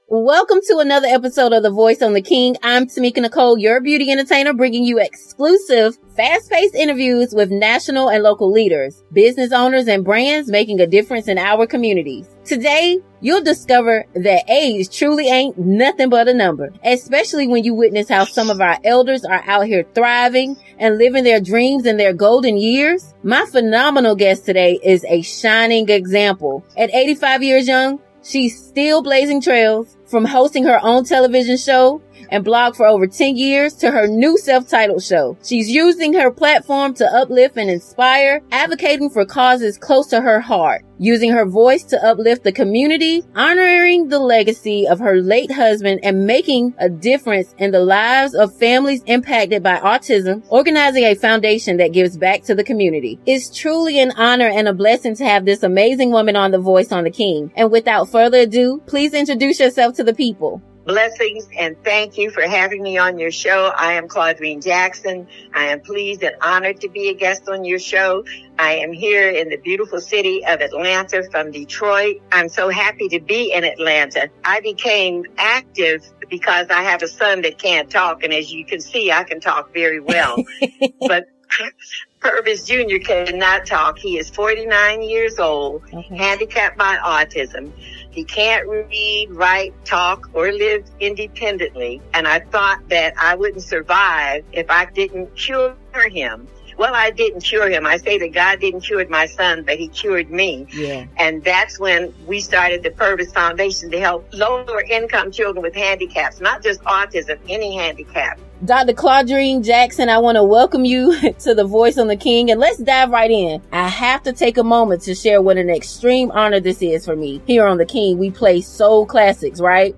fast-paced interviews with national and local leaders, business owners, and brands making a difference in our communities.